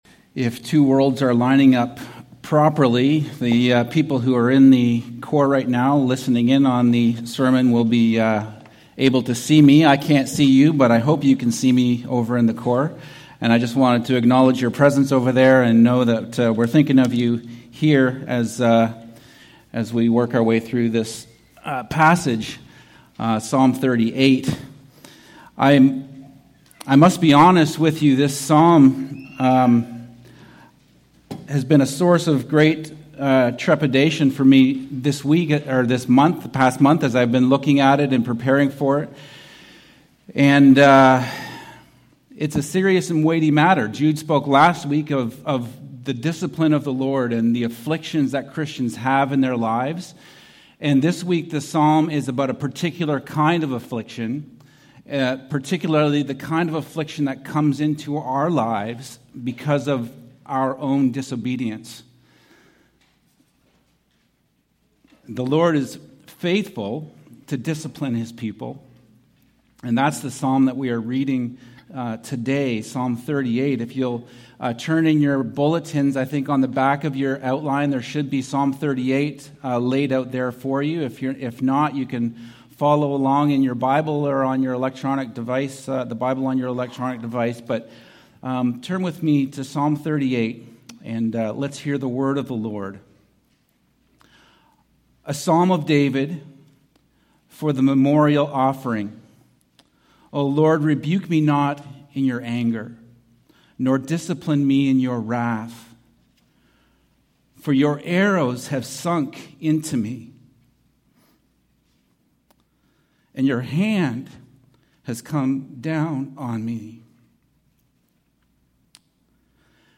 Sermon Archives Psalm38